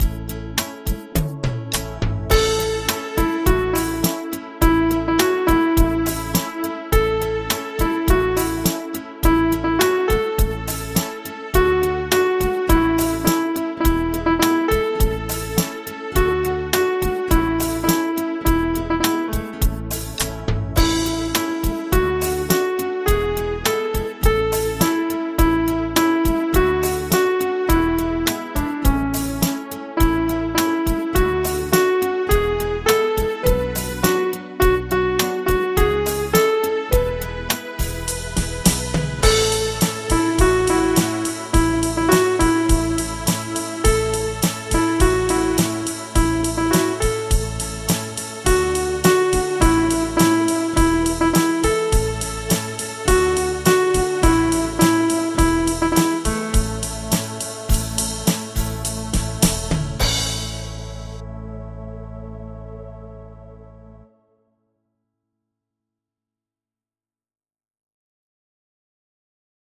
Audio Midi Bè Bass: download